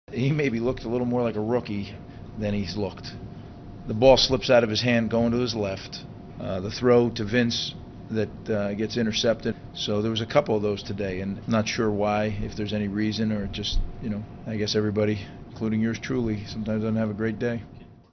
Greg Schiano – Buccaneers Head Coach: “He [Glennon] maybe looked a little more like a rookie than he’s looked.”